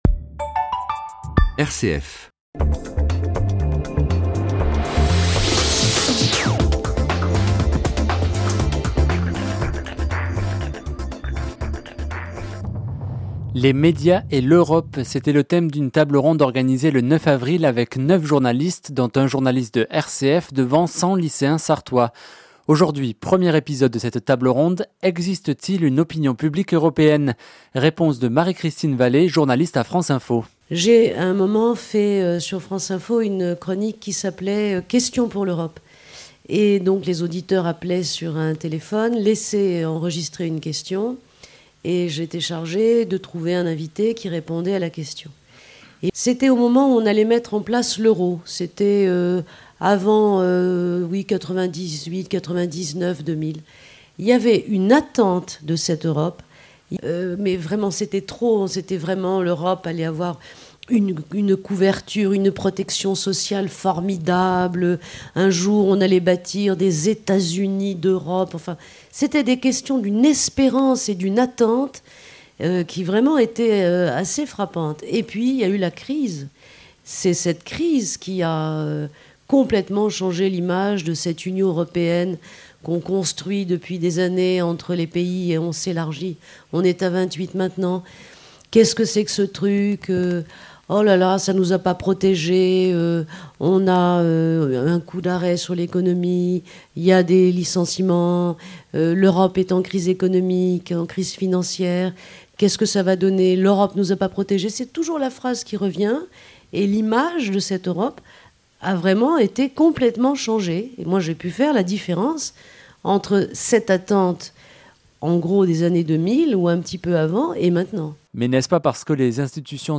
Débat public : la place du citoyen dans les radios associatives locales
Débat / table ronde